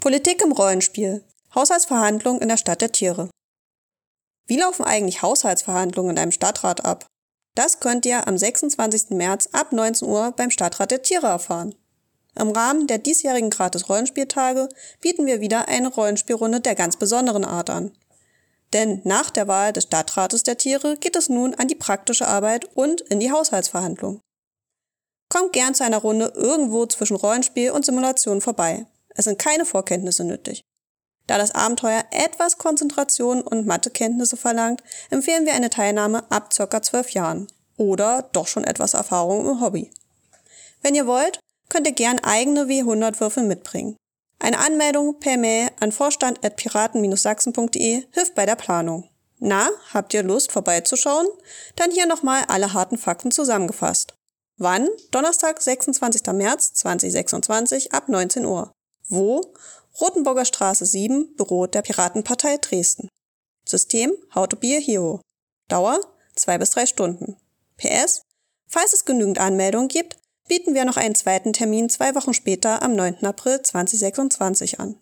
Text als Hörfassung: ▶